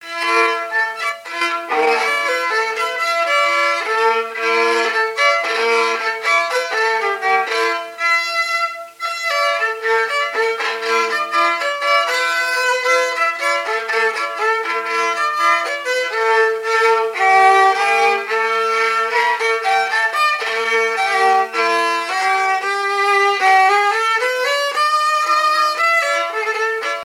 Localisation Montreuil (Plus d'informations sur Wikipedia)
Fonction d'après l'analyste danse : quadrille : chaîne anglaise ;
Usage d'après l'analyste gestuel : danse ;
Catégorie Pièce musicale inédite